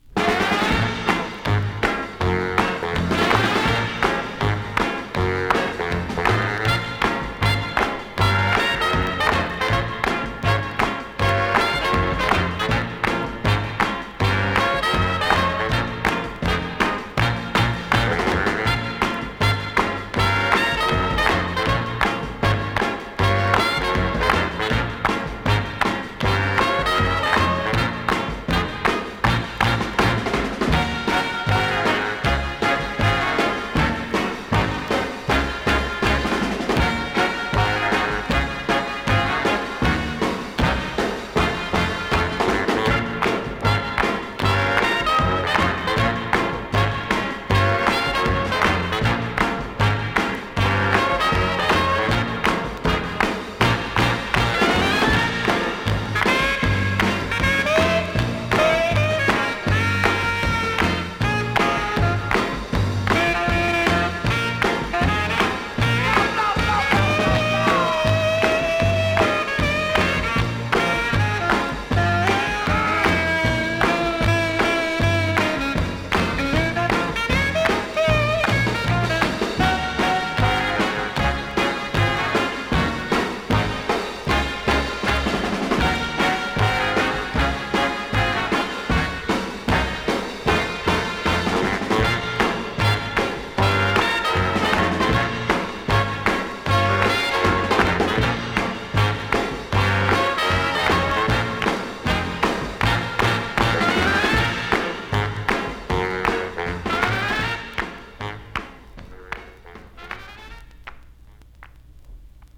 Vinyl, LP, Stereo
Genre:    Pop, Folk, World, & Country
Style:    Nordic